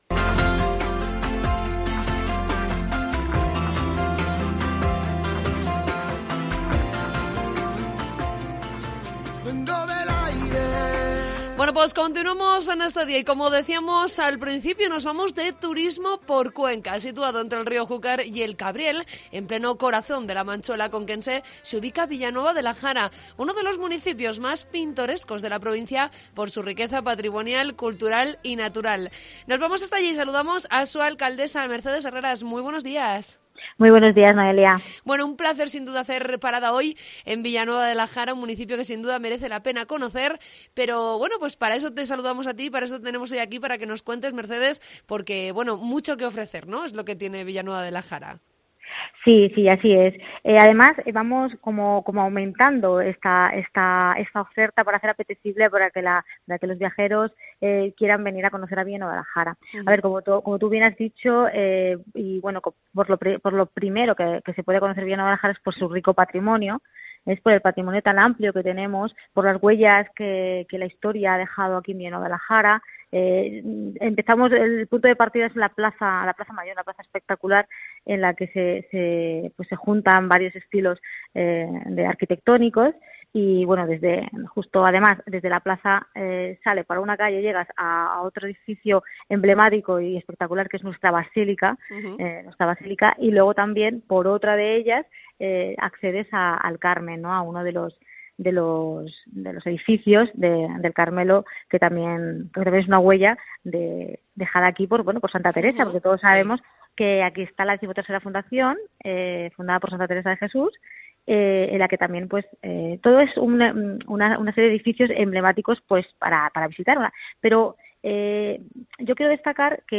Entrevista con la alcaldesa de Villanueva de la Jara, Mercedes Herreras